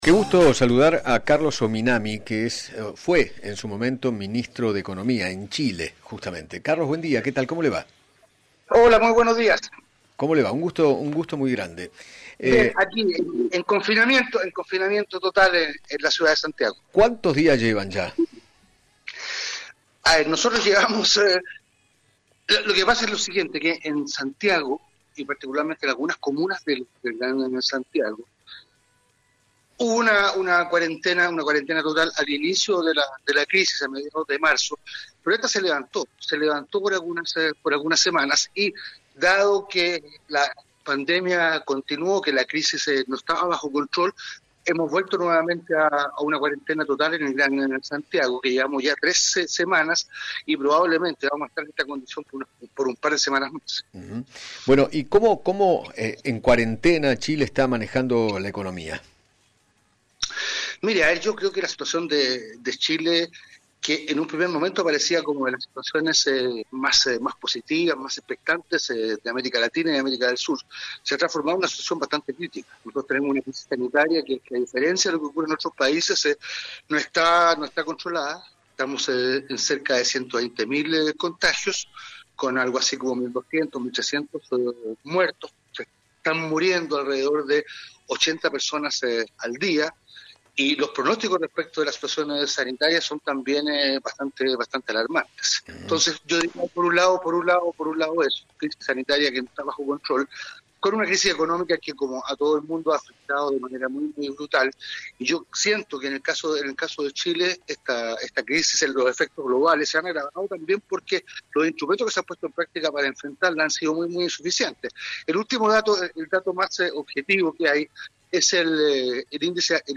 Carlos Ominami, exministro de Economía chileno, dialogó con Eduardo Feinmann sobre la evolución del coronavirus en Chile y se refirió a la crisis económica ocasionada por la pandemia.